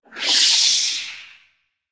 character_reappear.ogg